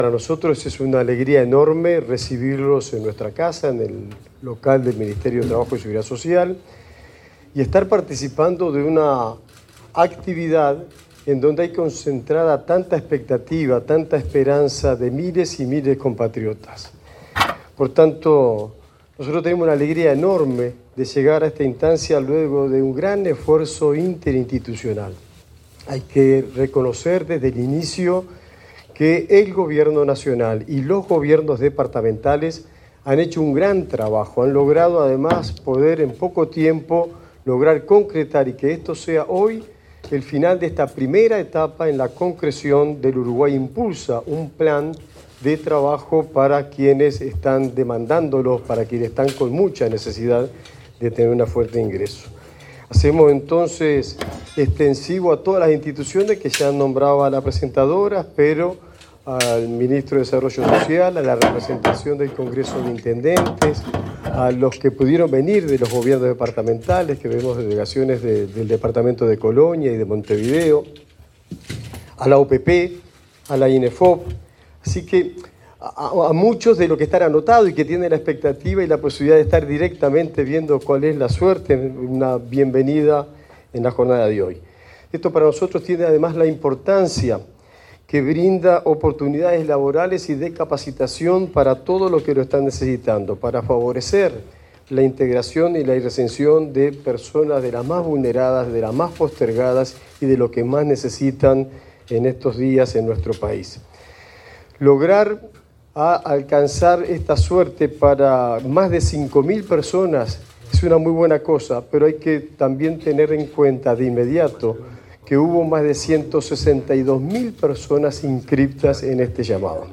Palabras de los ministros de Trabajo, Juan Castillo, y de Desarrollo Social, Gonzalo Civila
Palabras de los ministros de Trabajo, Juan Castillo, y de Desarrollo Social, Gonzalo Civila 29/08/2025 Compartir Facebook X Copiar enlace WhatsApp LinkedIn Durante el primer sorteo del programa Uruguay Impulsa, para el acceso a puestos laborales temporales, el ministro de Trabajo y Seguridad Social, Juan Castillo, y su par de Desarrollo Social, Gonzalo Civila, hicieron uso de la palabra.
oratorias.mp3